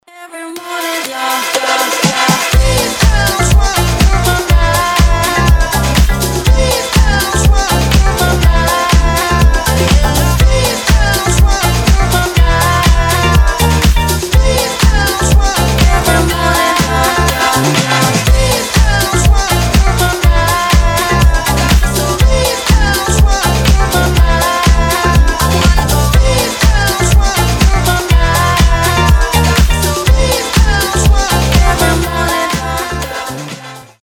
Классный диско рейв